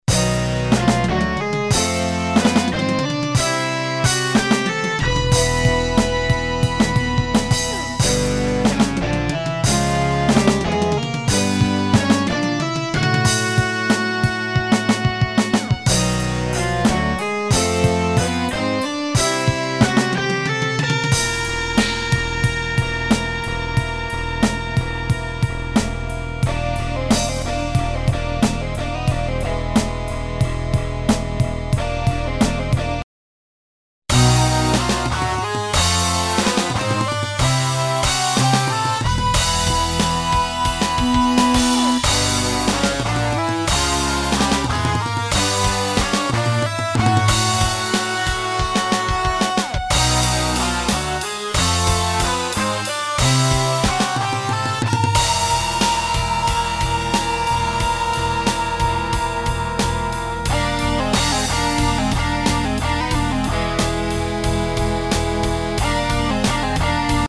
Между ними пауза в 1 секунду.. чтоб отдышаться от каки. :-)
НИКАКОЙ дополнительной обработки не производилось.